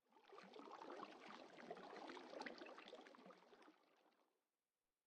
Minecraft Version Minecraft Version 1.21.5 Latest Release | Latest Snapshot 1.21.5 / assets / minecraft / sounds / ambient / underwater / additions / water1.ogg Compare With Compare With Latest Release | Latest Snapshot
water1.ogg